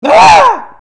Download Goofy ah sound effect for free.